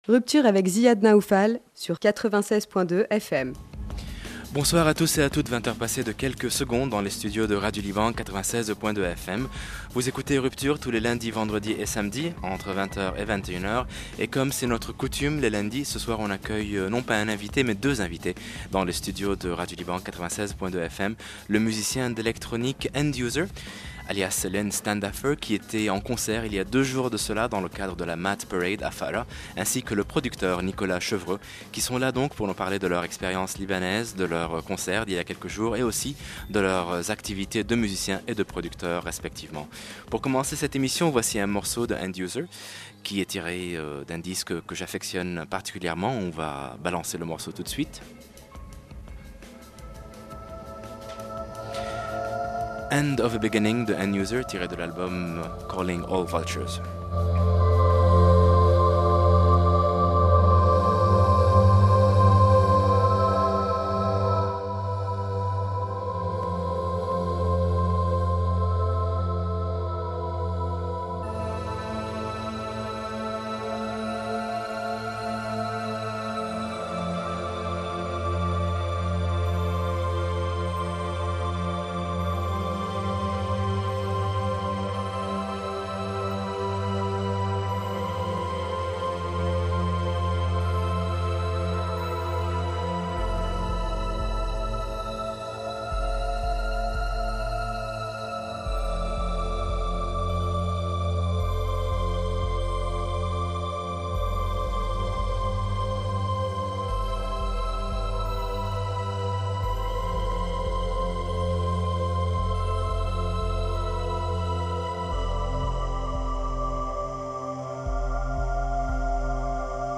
breakcore/drum’n’bass
an exclusive interview and live performance